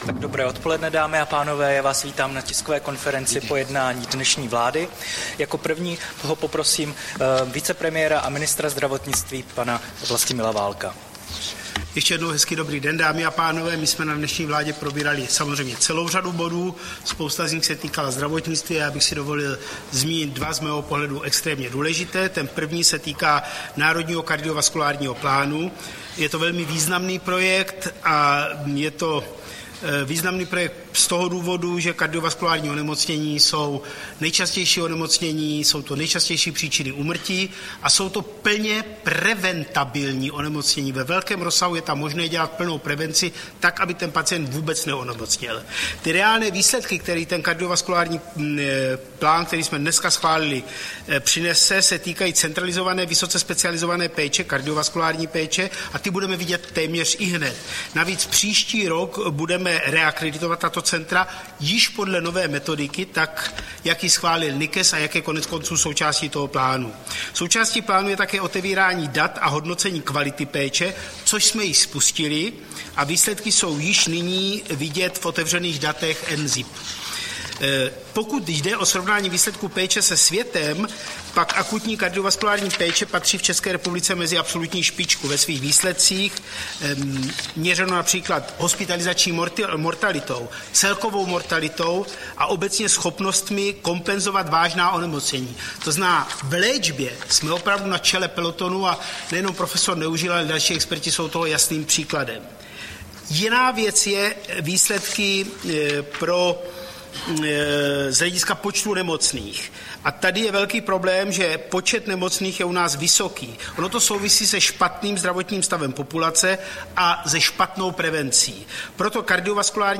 Tisková konference po jednání vlády, 11. prosince 2024